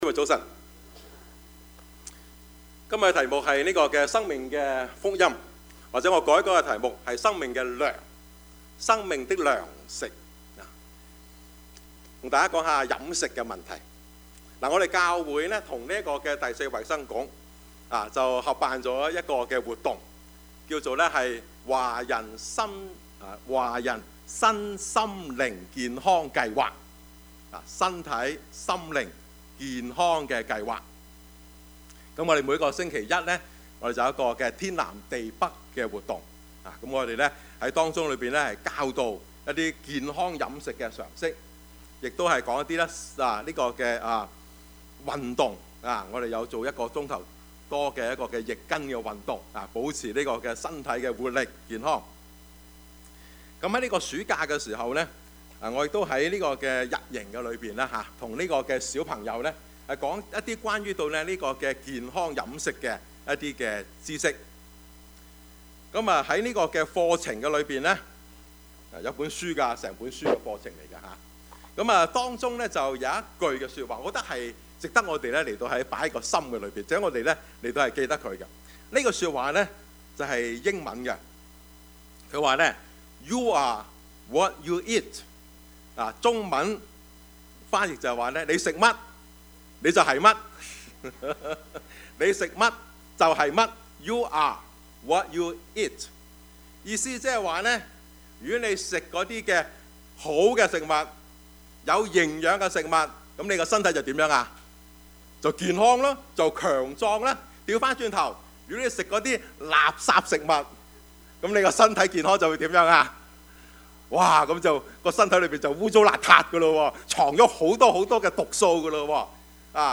Passage: 約 翰 福 音 6:51-58 Service Type: 主日崇拜
Topics: 主日證道 « 我為錢狂?